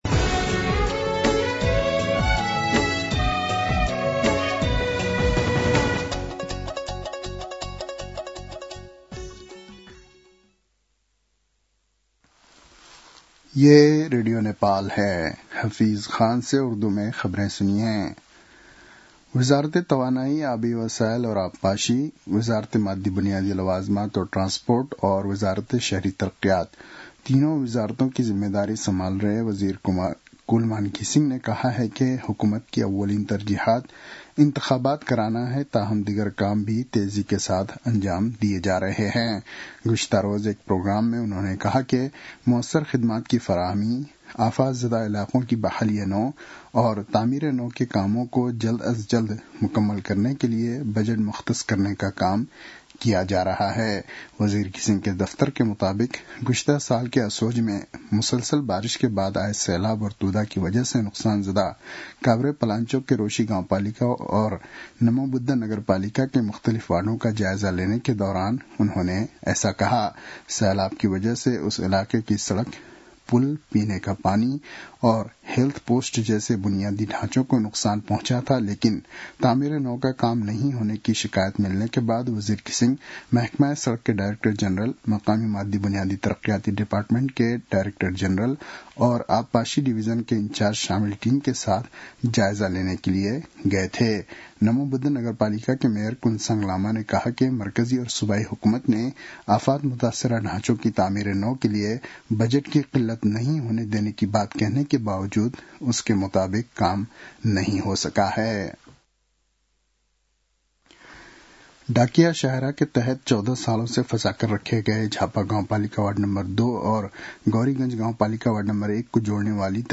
उर्दु भाषामा समाचार : १३ मंसिर , २०८२